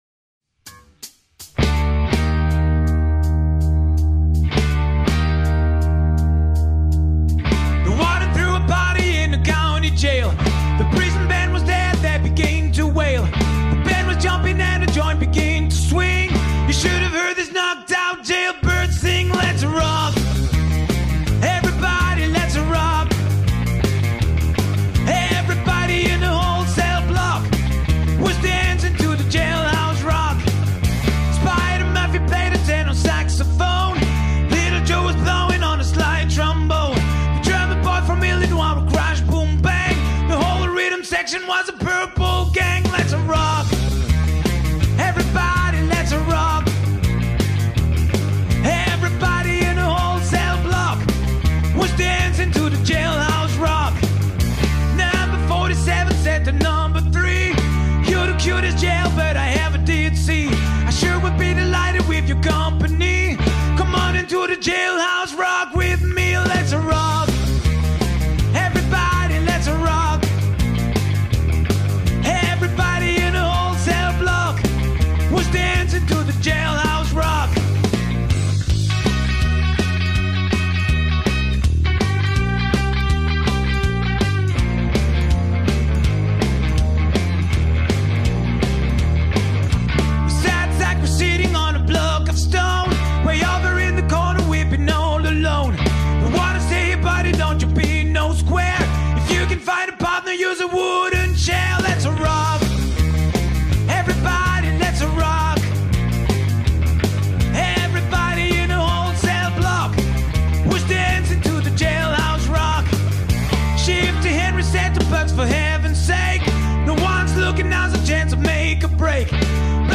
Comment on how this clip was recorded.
Ilok - Božić 2018 LIVE sa božićne proslave u Iloku, 2018. godine.